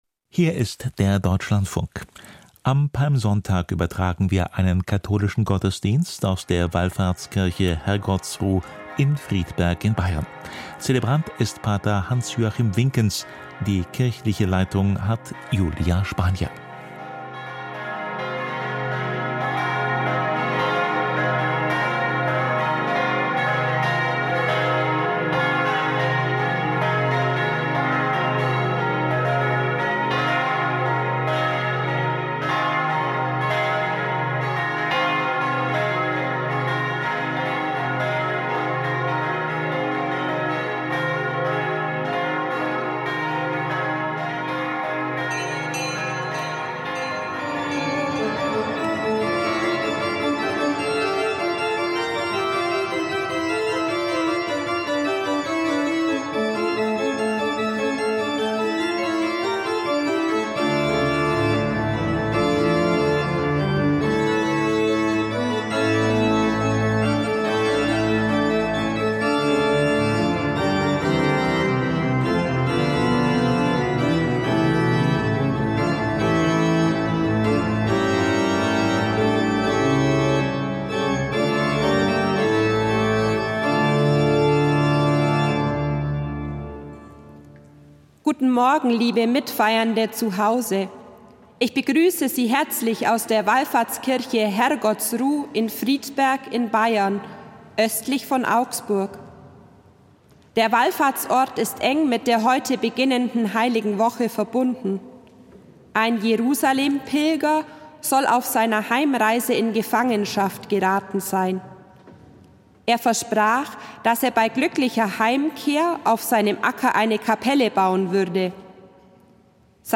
Katholischer Gottesdienst aus der Wallfahrtskirche Herrgottsruh in Friedberg